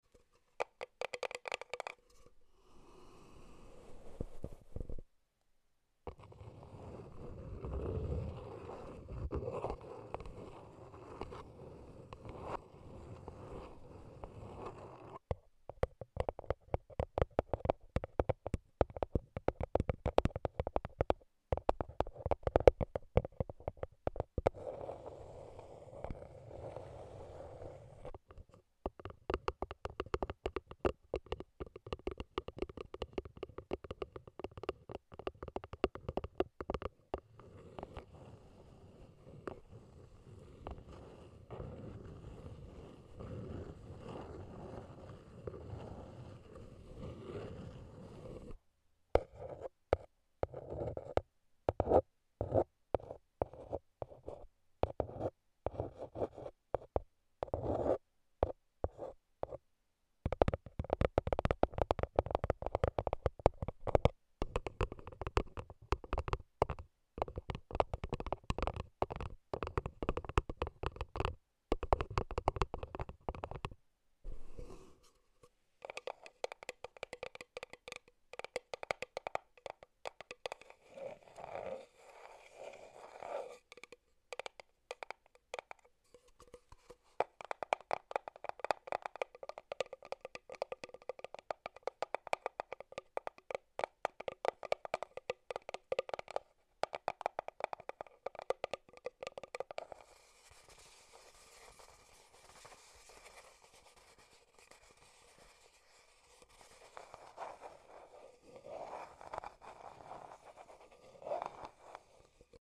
Some Crispy ASMR Taps AND Sound Effects Free Download